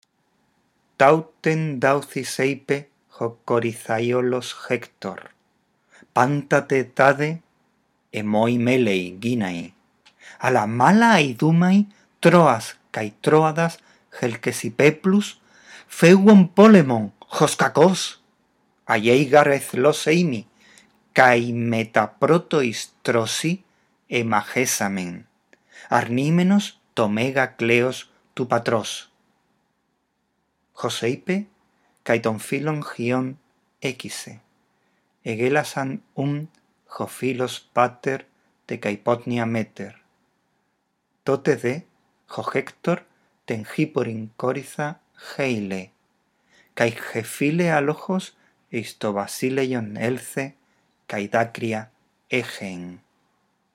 Lee el texto en voz alta, respetando los signos de puntuación. Después escucha estos tres archivos de audio y repite la lectura.